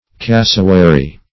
Cassowary \Cas"so*wa*ry\, n.; pl.